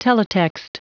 Prononciation du mot teletext en anglais (fichier audio)
Prononciation du mot : teletext